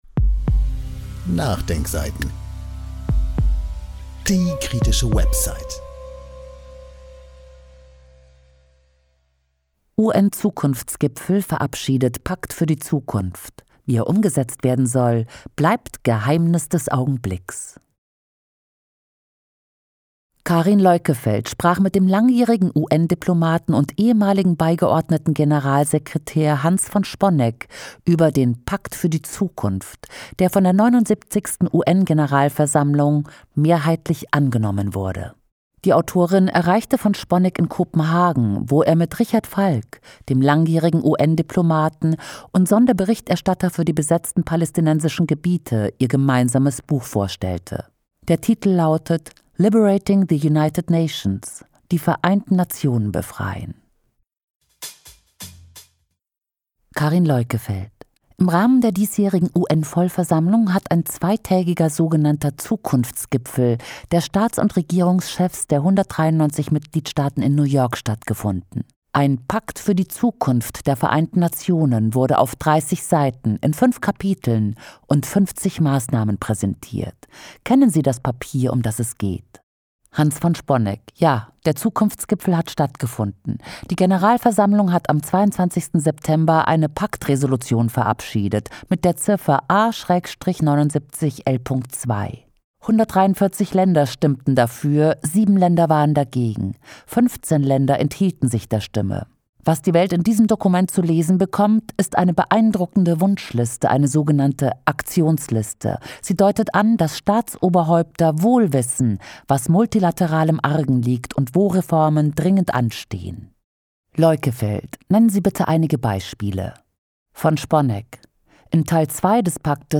Das Interview: